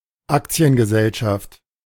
Aktiengesellschaft (German pronunciation: [ˈaktsi̯ənɡəˌzɛlʃaft]
De-Aktiengesellschaft.ogg.mp3